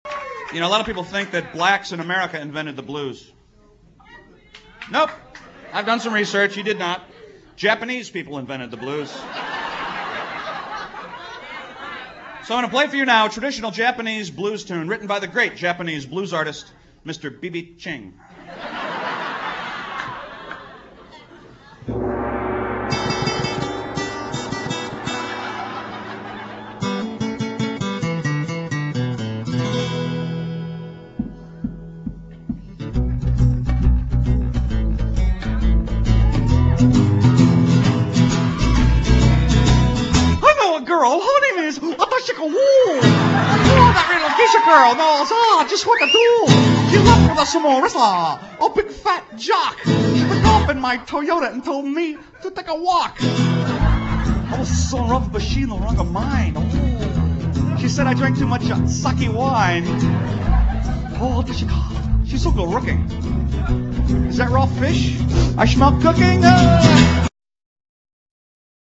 (COMEDY SONGS)